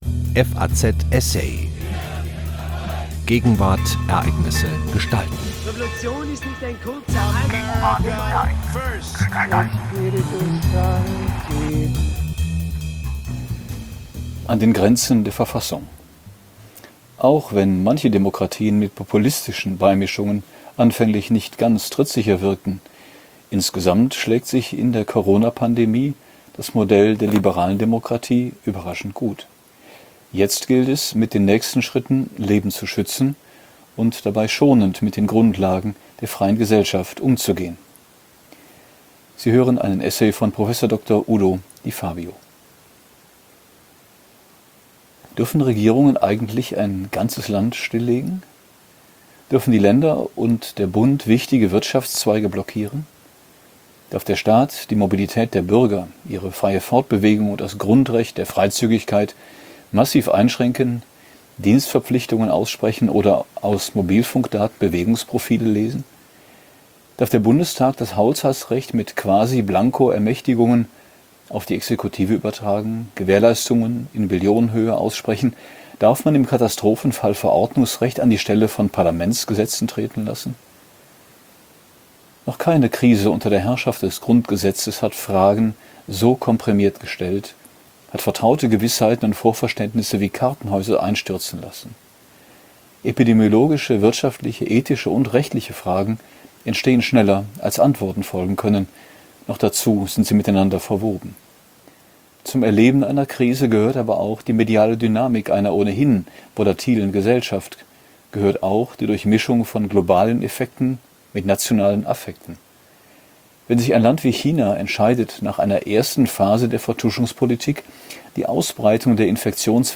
Ein Blick zurück auf die Studentenrevolte von 1968, die von Markus Söder angezettelte Kreuz-Debatte oder der katalanische Nationalismus: Der neue Podcast FAZ Essay widmet sich jede Woche aktuellen politischen und gesellschaftlichen Ereignissen – und gibt ihnen mit geistreichen Beiträgen von Wissenschaftlern und Politikern Tiefe und Substanz.